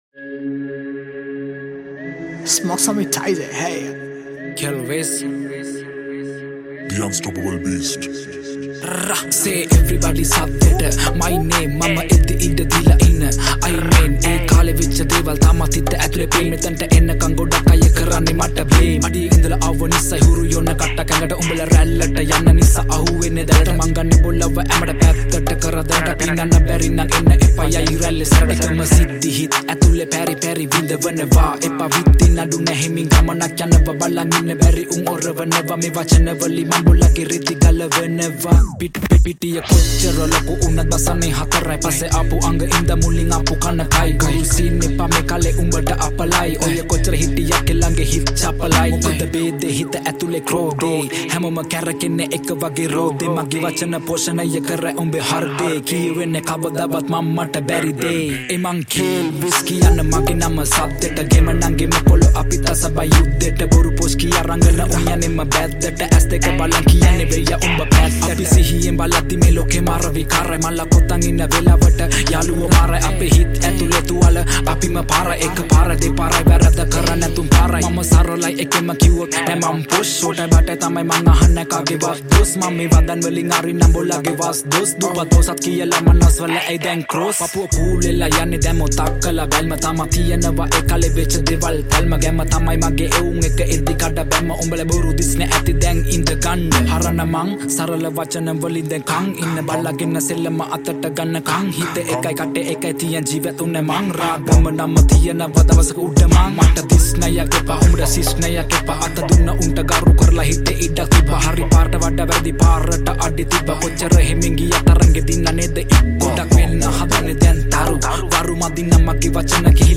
High quality Sri Lankan remix MP3 (2.6).
Rap